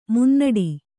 ♪ munnaḍi